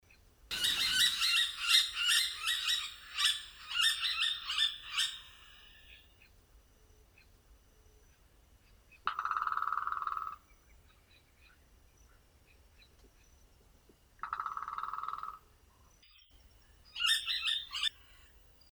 Yellow-fronted Woodpecker (Melanerpes flavifrons)
Sex: Both
Life Stage: Adult
Location or protected area: Reserva Privada y Ecolodge Surucuá
Condition: Wild
Certainty: Photographed, Recorded vocal